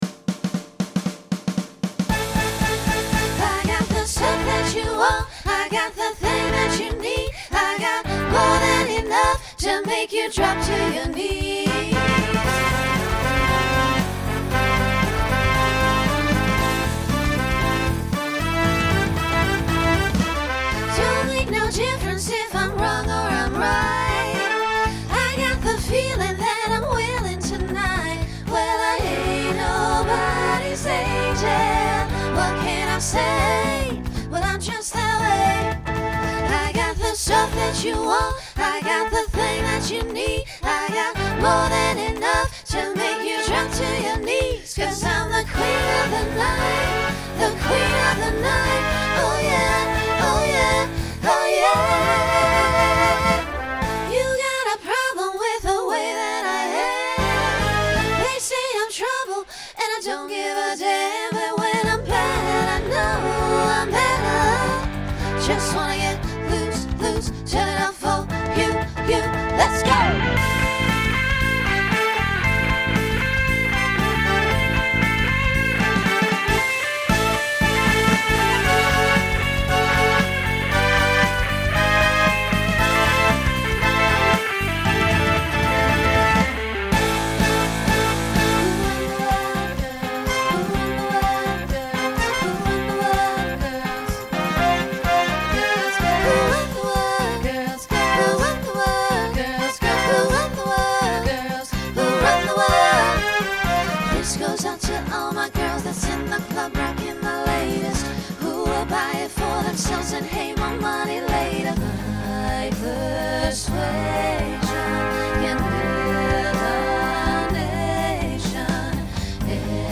Genre Pop/Dance , Rock
Voicing SSA